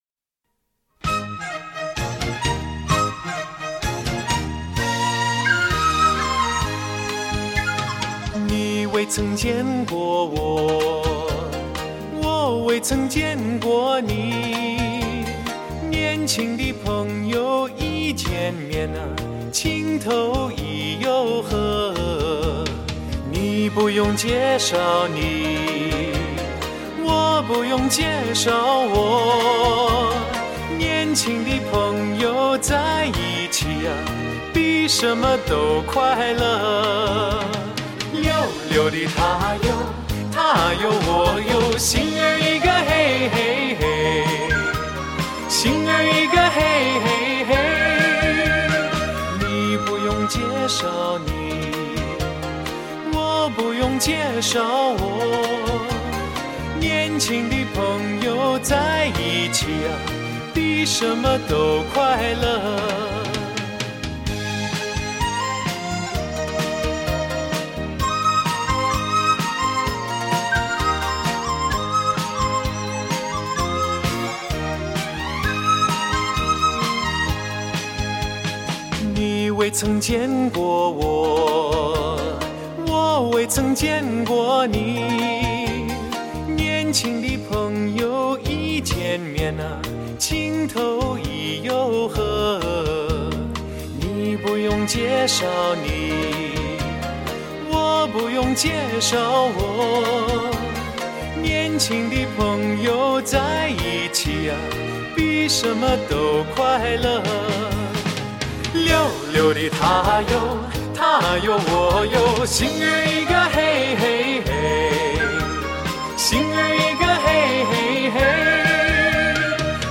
低音质128K